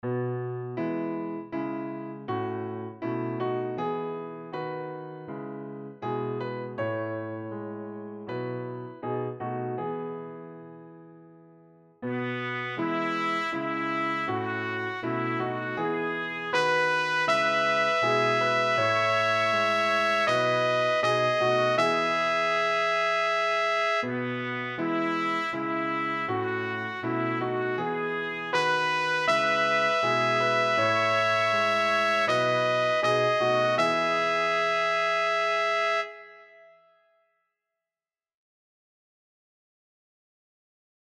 Click here to listen to audio sample-Descant